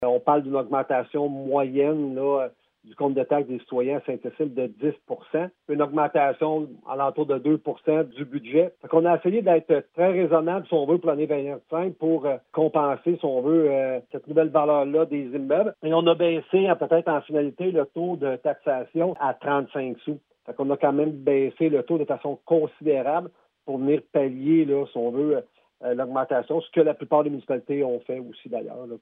Afin de pallier à cette importante hausse de la valeur, les élus ont décidé de diminuer le taux de taxation comme l’indique le maire Paul Sarrazin :